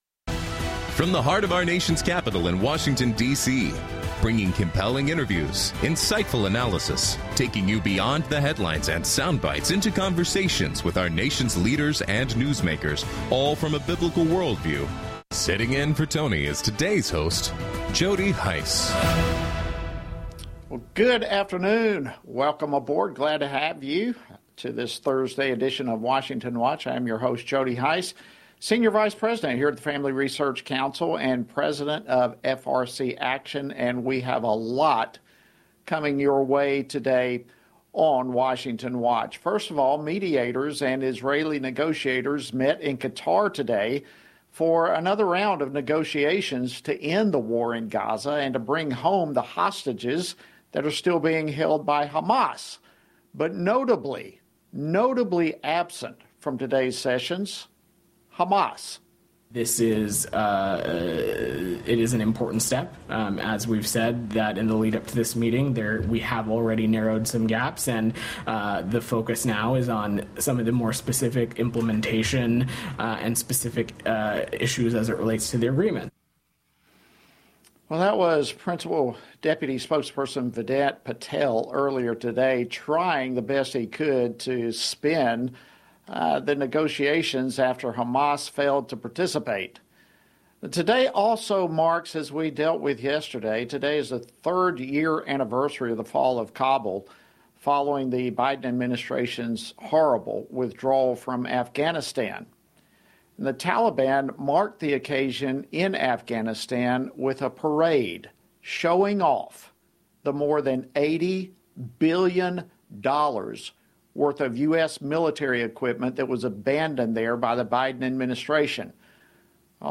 Hard hitting talk radio never has been and never will be supported by the main stream in America! Liberty News Radio is taking on the main stream press like never before!